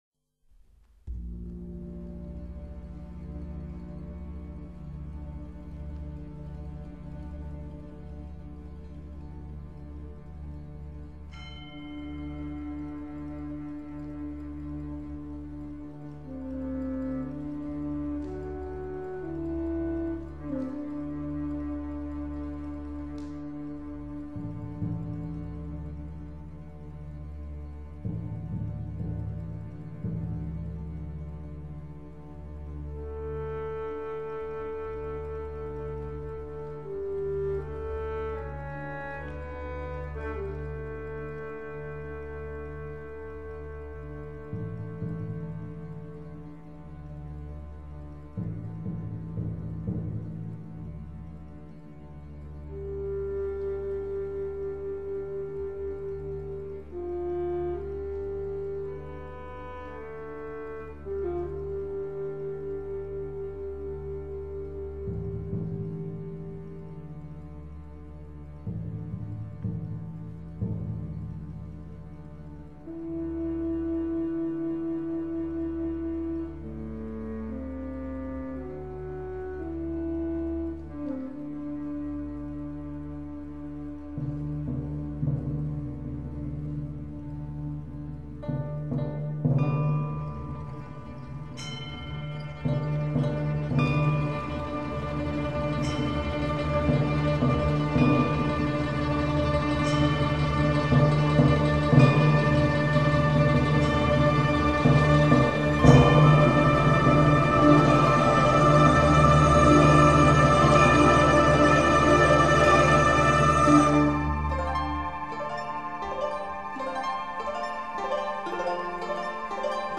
的慢板旋律非常动人，值得一听喔！！